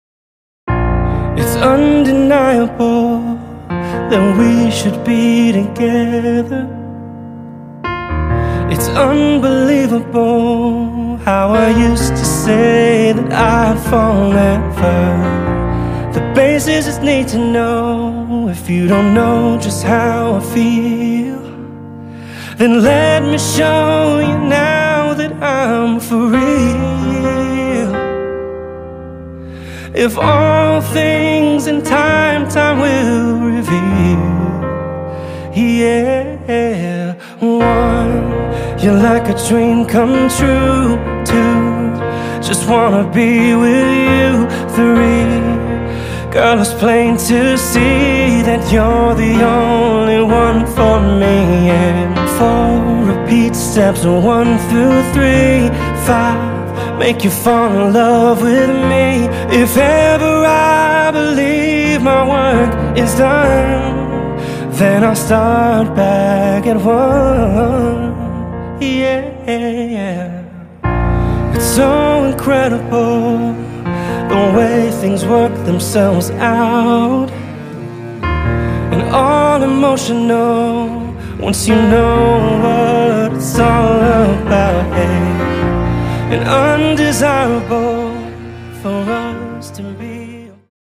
piano acoustic cover